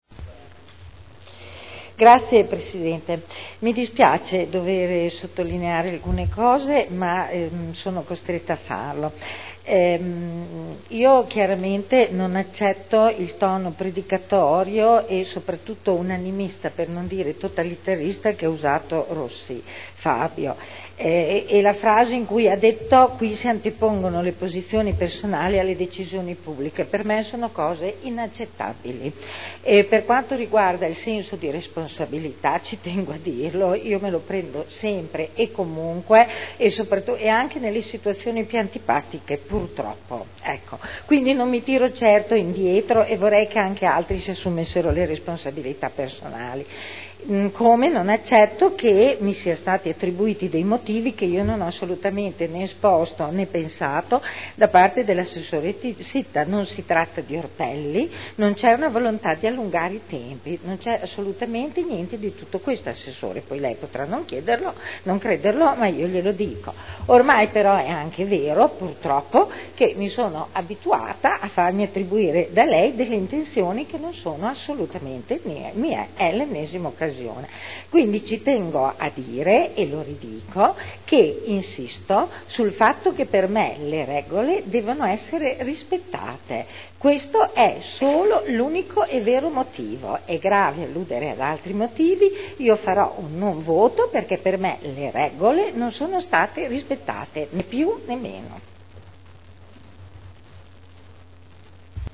Seduta del 13/12/2010 Deliberazione: Variante al P.O.C.-RUE – AREA in via Emilia Ovest Z.E. 1481-1502 – Adozione Dichiarazioni di voto